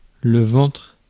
Ääntäminen
UK : IPA : /ˈwuːm/ US : IPA : [ˈwuːm]